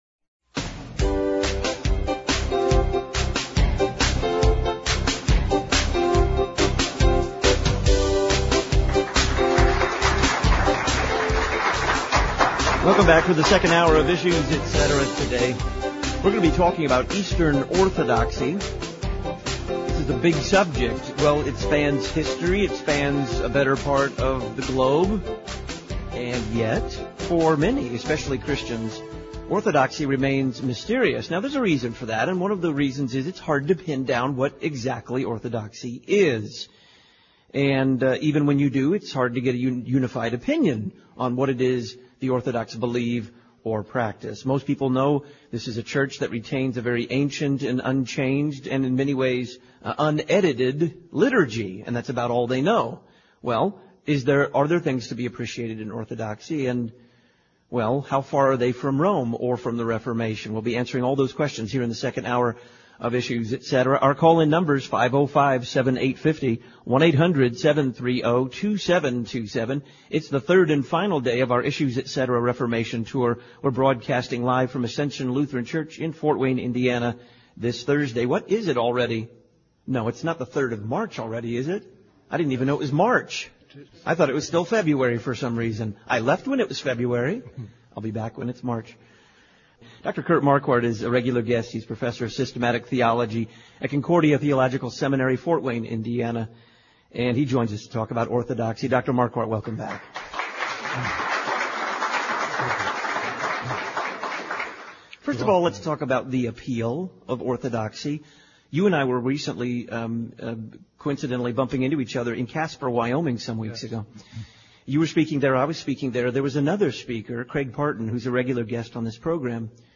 Eastern Orthodoxy (second interview)